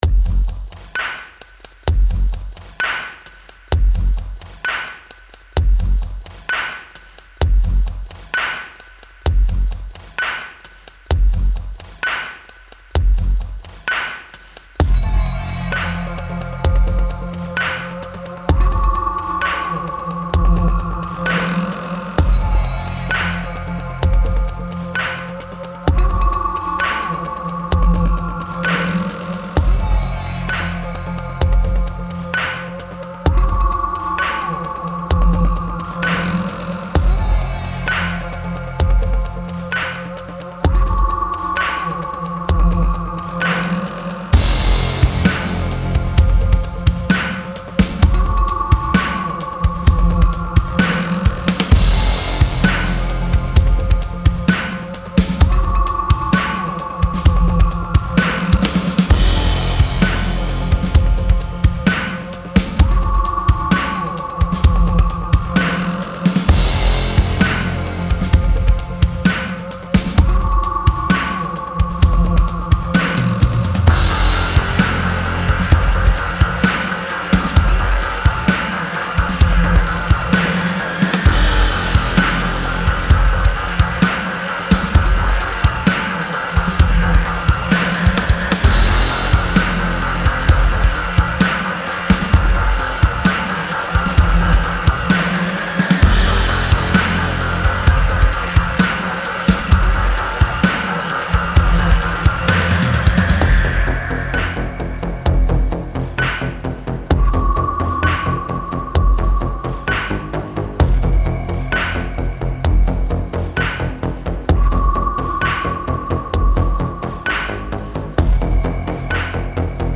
Creator of electronic, industrial and metal music.